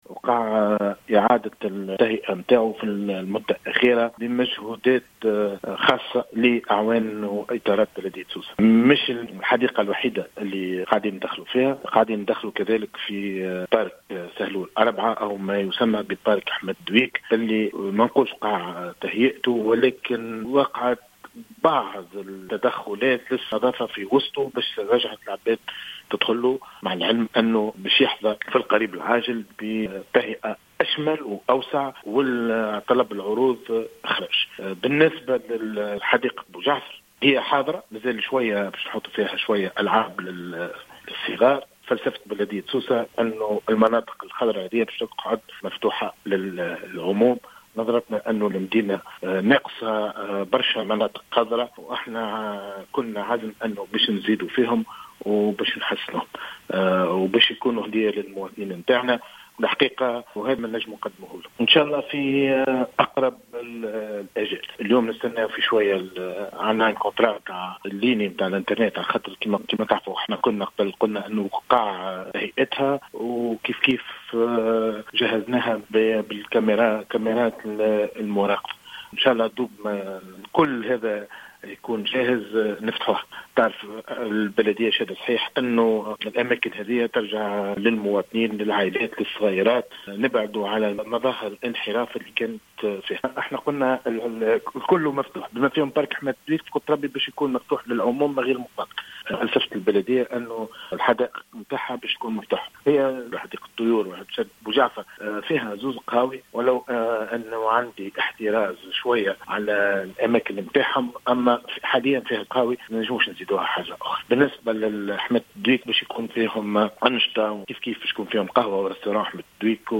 وقال رئيس بلدية سوسة، محمد إقبال في تصريح اليوم لـ"الجوهرة أف أم" إن البلدية قد أخذت على عاتقها إعادة تهيئة بعض الحدائق والمناطق الخضراء على غرار حديقة سهلول 4.